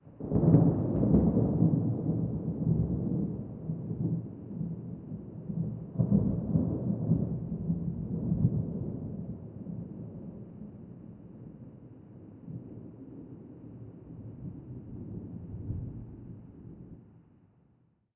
1Shot Weather Thunderclap ST450 01_ambiX.wav